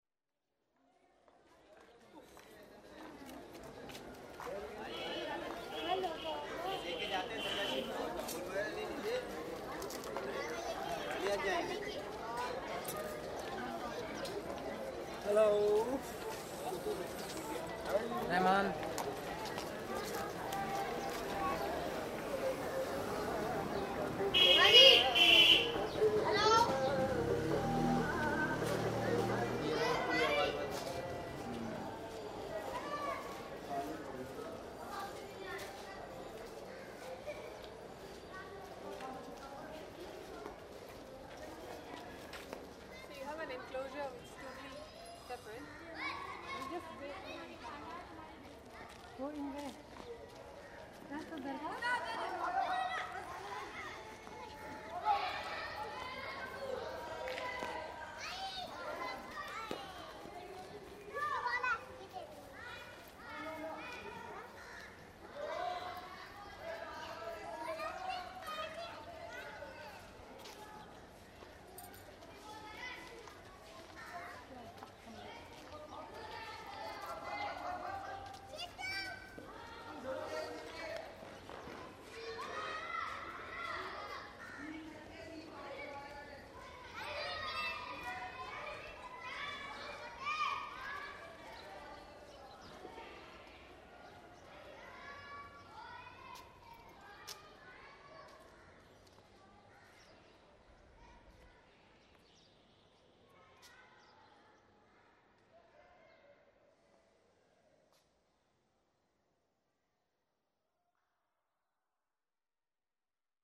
Soundcities, Residential area in Nizamuddin1:53
hildegard_westerkamp_-_12_-_residential_area_in_nizamuddin.mp3